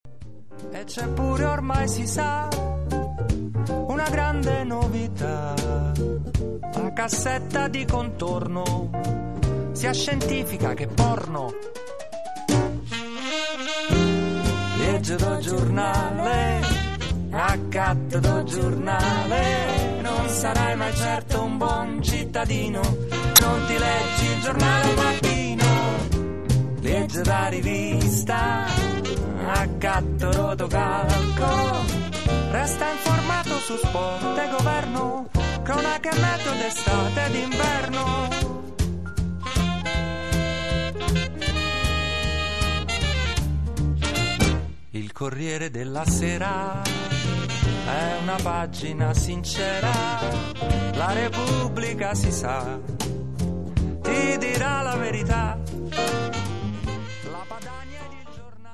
piano e voce
contrabbasso
batteria
sax tenore e cori
sax alto
chitarra